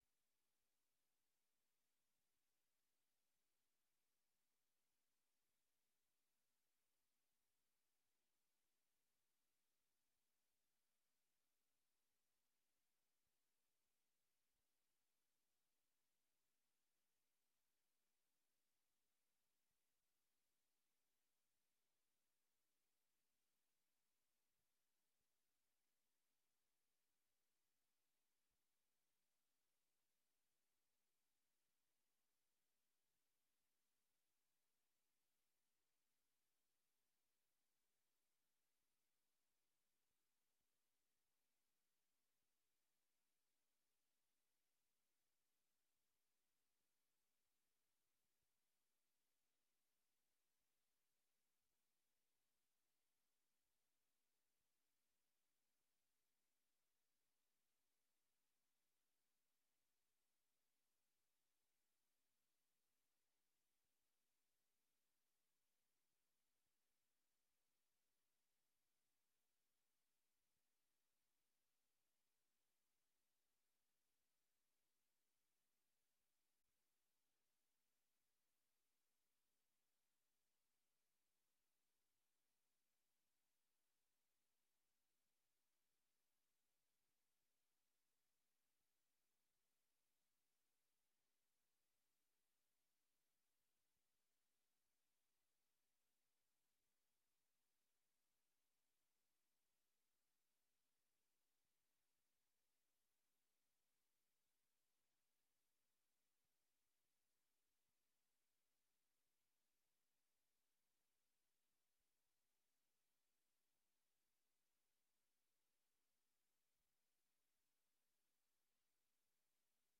Raadsvergadering 29 maart 2022 19:30:00, Gemeente Dronten
Locatie: Raadzaal